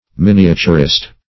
\Min"i*a*tur`ist\
miniaturist.mp3